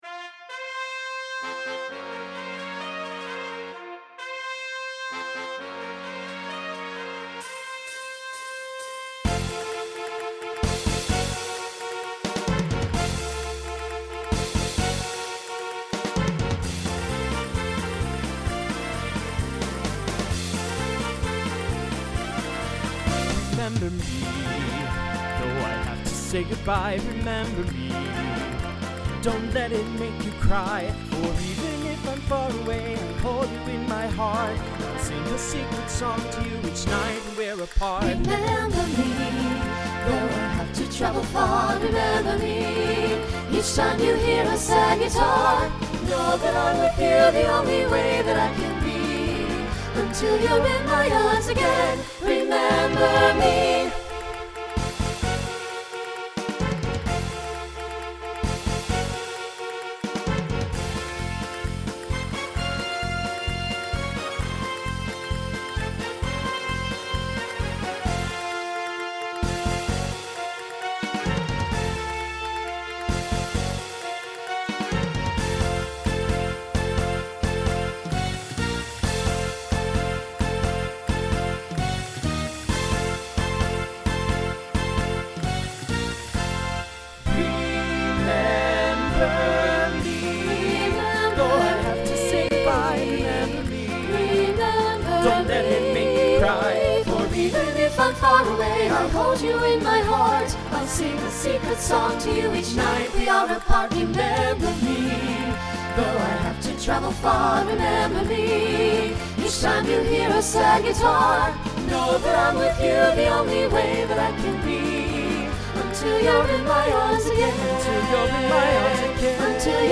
Mid-tempo